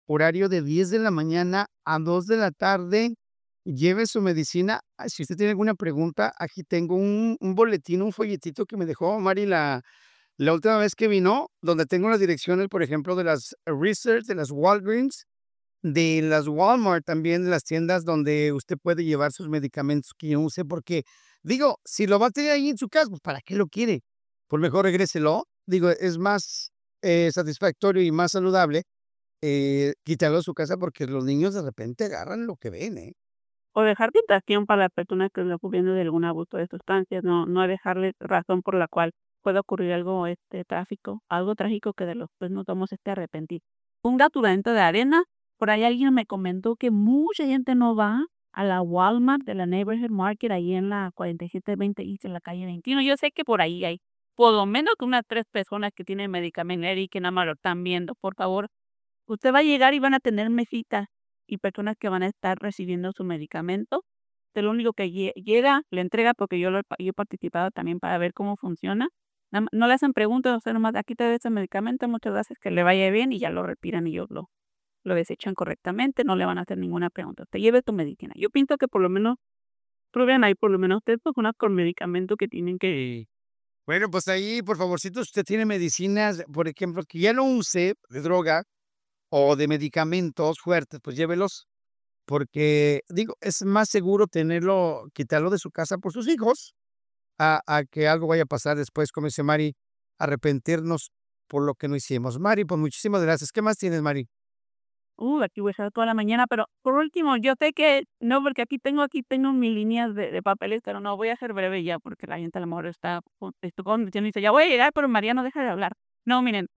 quien participó como invitada especial en nuestra cabina